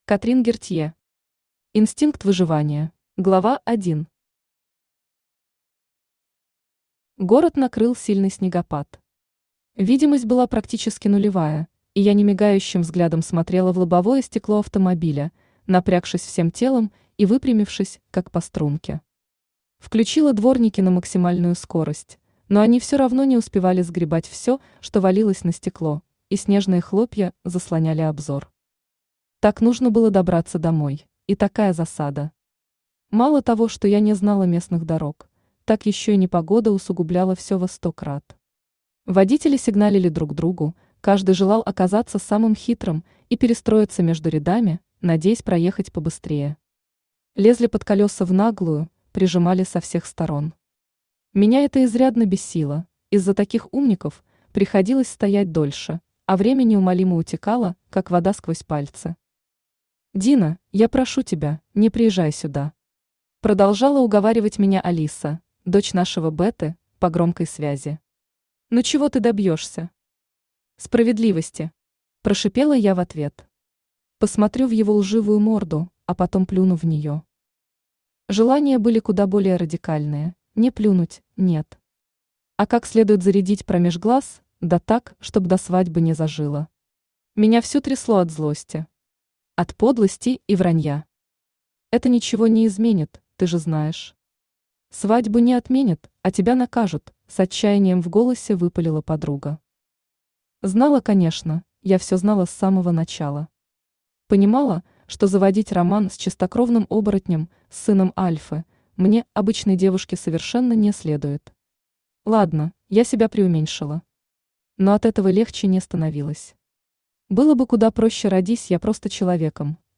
Аудиокнига Инстинкт выживания | Библиотека аудиокниг
Aудиокнига Инстинкт выживания Автор Катрин Гертье Читает аудиокнигу Авточтец ЛитРес.